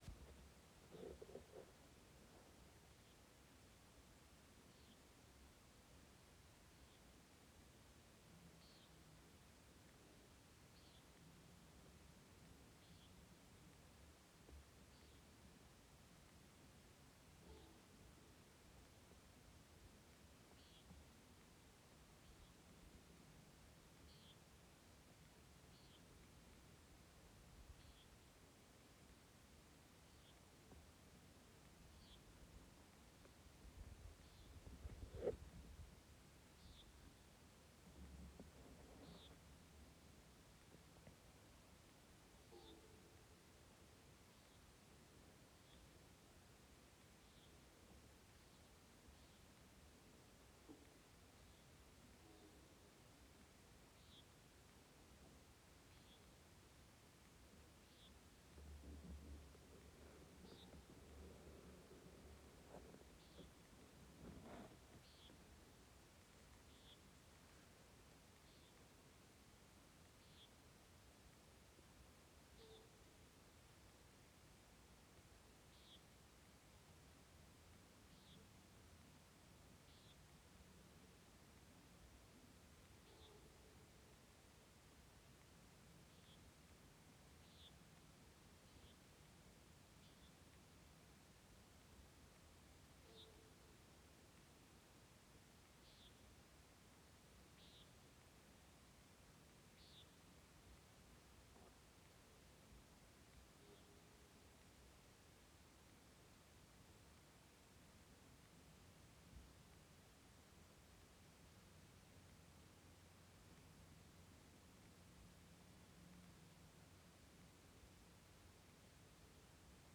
common nighthawk